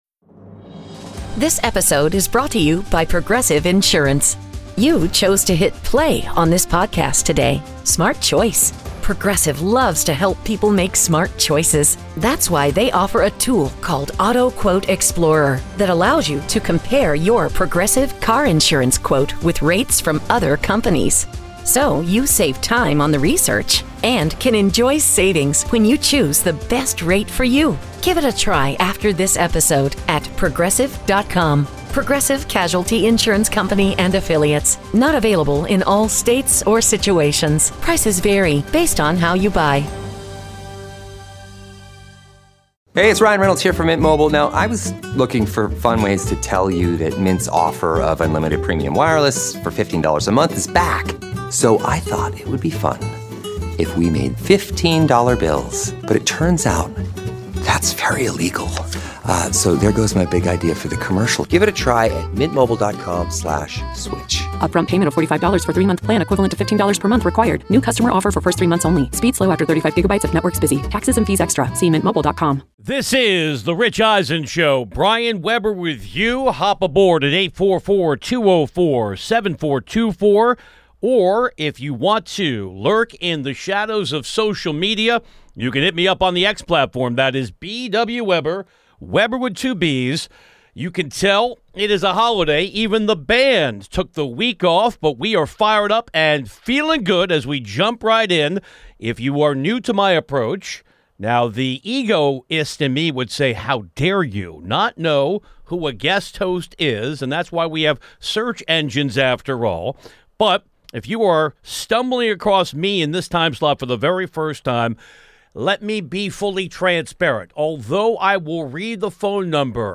Guest host